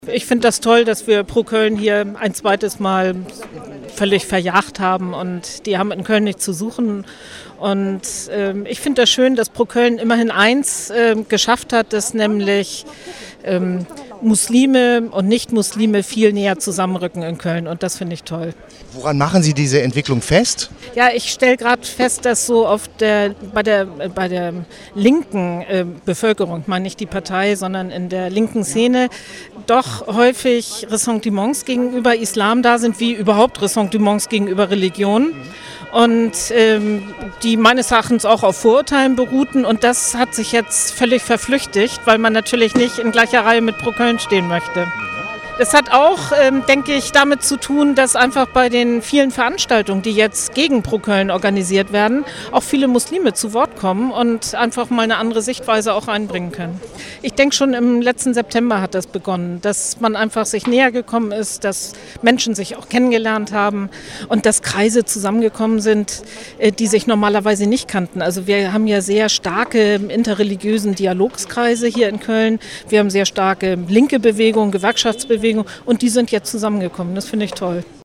Kölns Sozialdezernentin Marlies Bredehorst erklärte nach der Kundgebung der NRhZ: „Ich find das toll, dass wir ‚Pro Köln’ hier ein zweites Mal völlig verjagt haben.